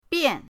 bian4.mp3